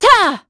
Valance-Vox_Attack4.wav